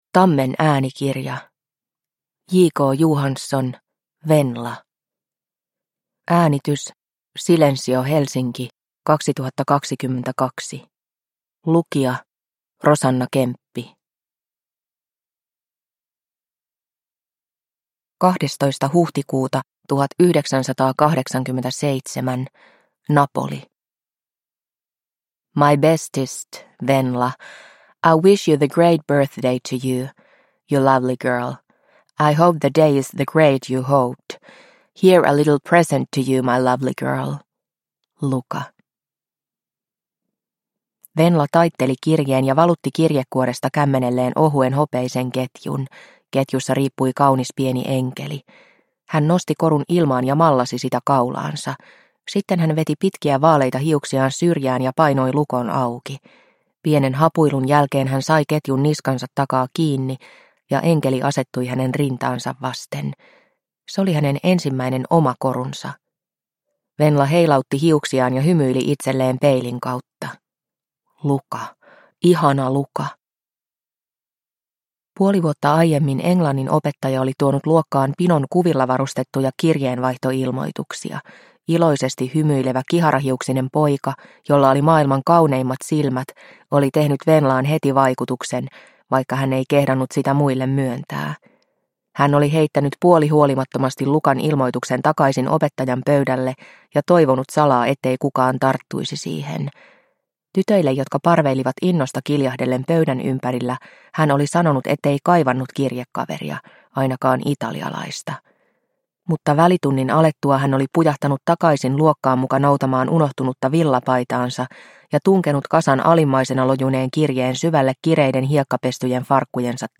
Venla – Ljudbok – Laddas ner